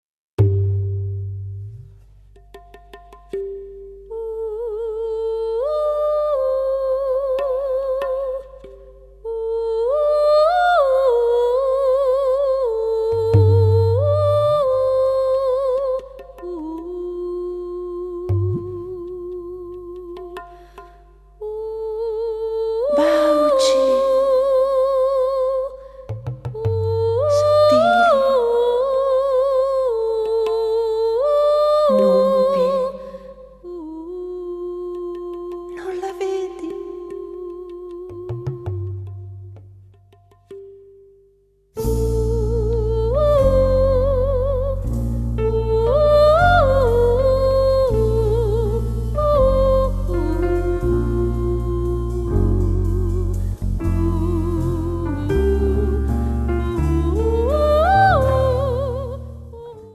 voce
sax soprano, flauto, flauto in sol